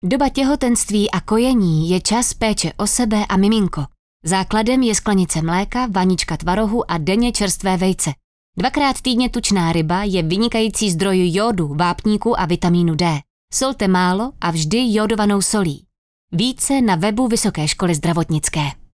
Letáky budou distribuovány do ambulantních gynekologicko-porodnických ordinací a audiospot bude vysílaný Českým rozhlasem v rámci Světového týdne kojení, který probíhá od 1. srpna do 8. srpna 2025.
Spot 1